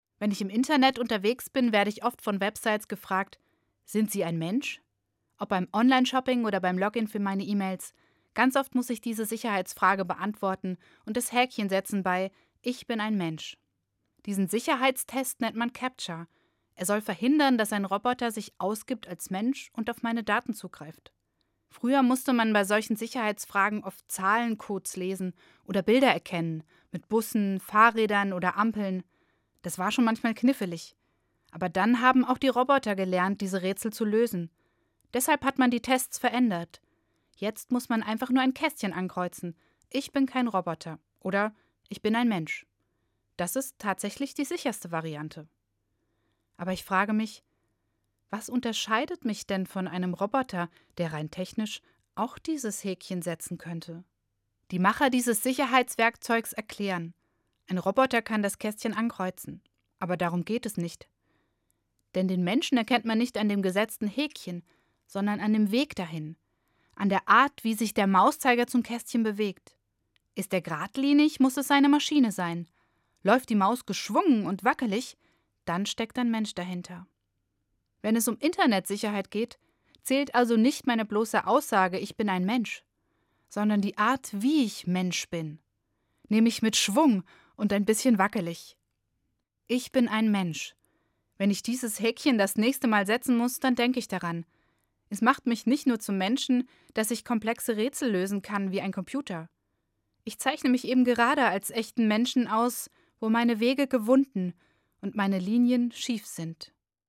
Evangelische Pfarrerin, Gießen